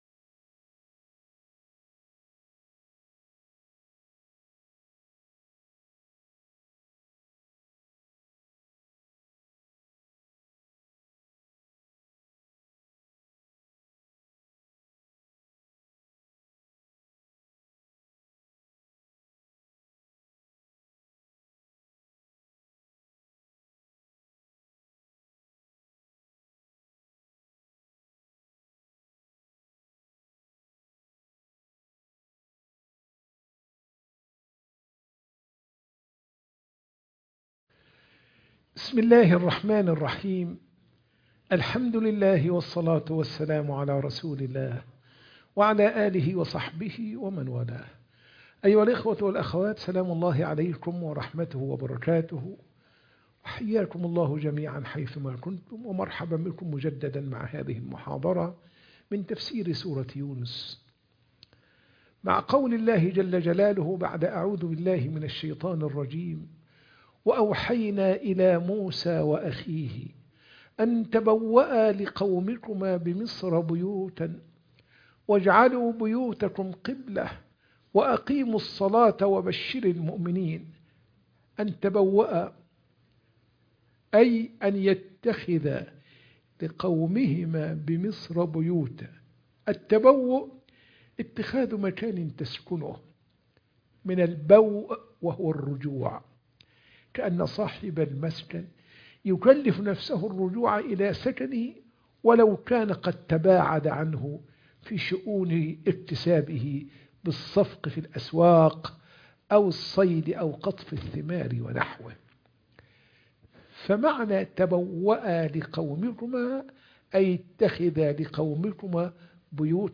تفسير سورة يونس 87 - المحاضرة 16